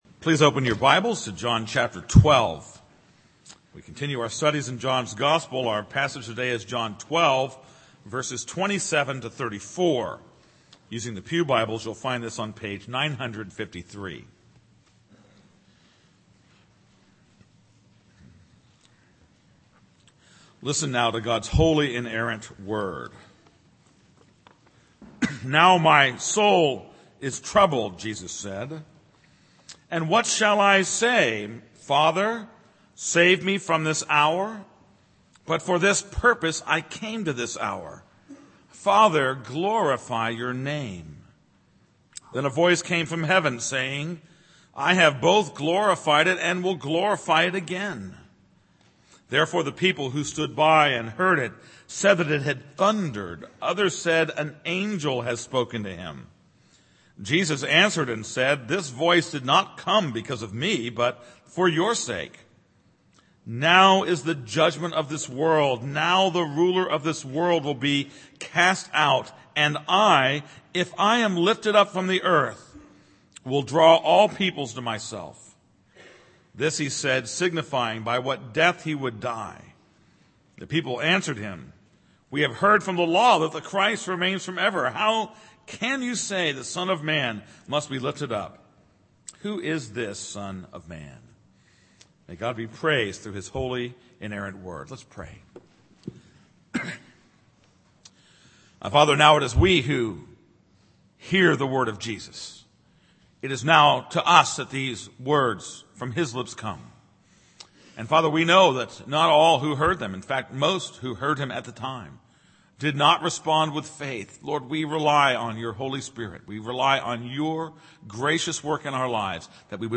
This is a sermon on John 12:27-34.